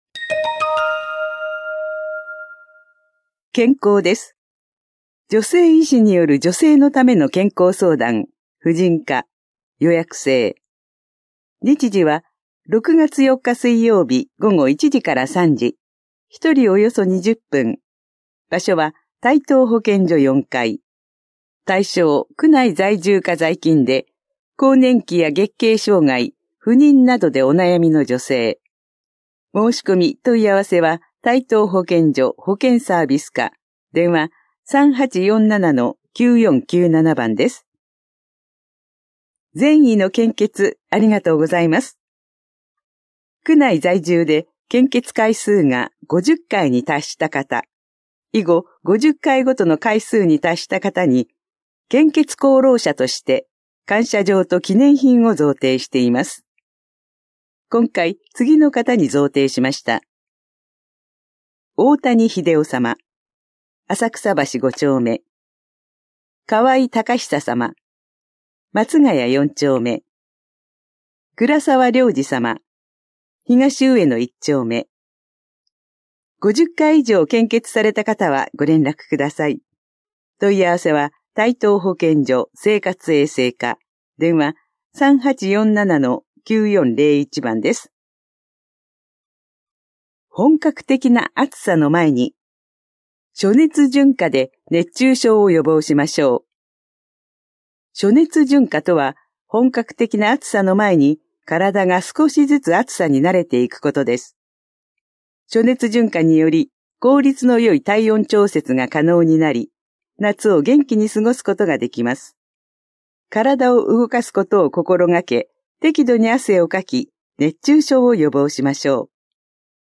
広報「たいとう」令和7年5月5日号の音声読み上げデータです。